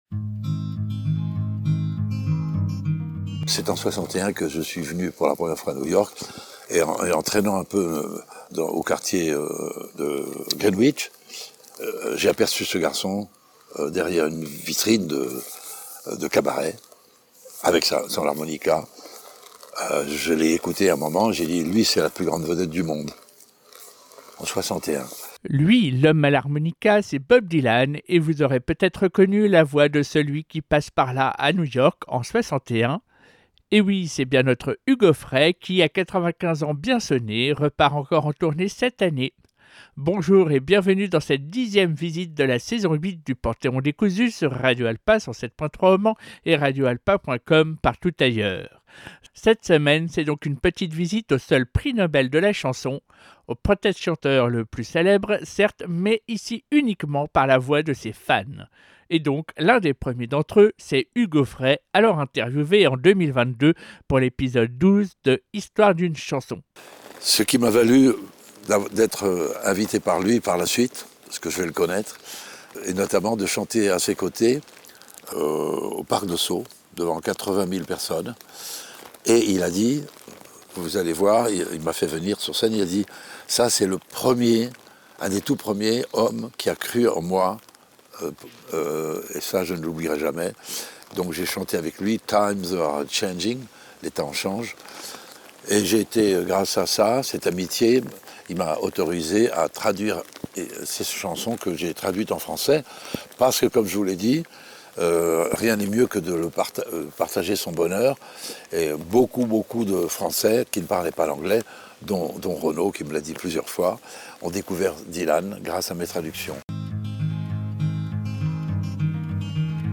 Quelques bonnes reprises de tubes